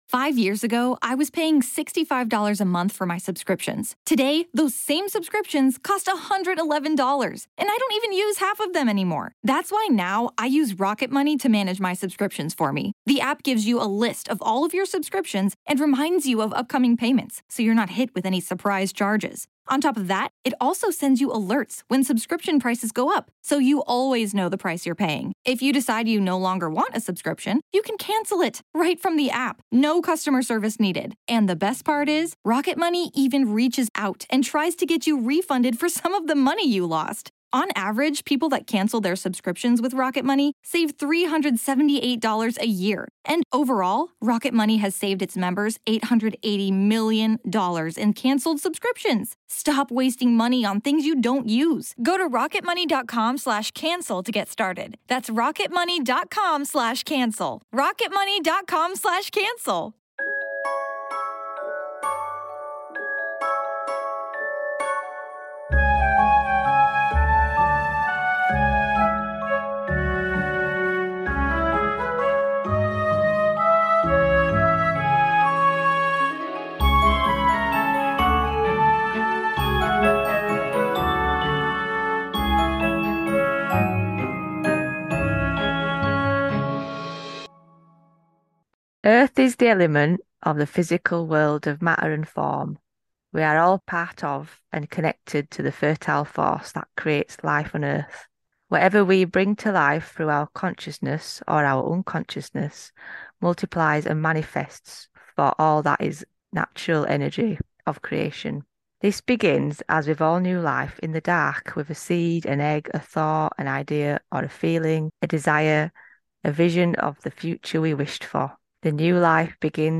Come sit with us two northern birds and have a laugh as we go off on tangents.